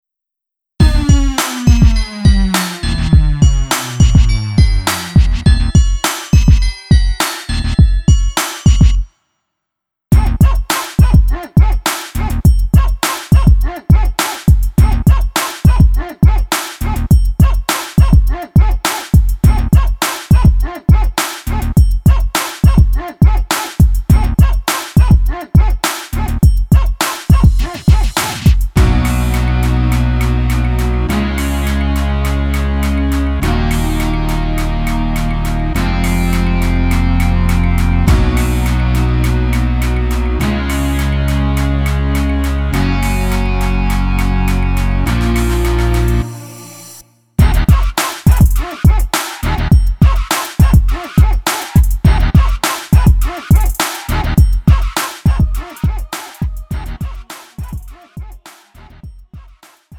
음정 -1키 3:11
장르 가요 구분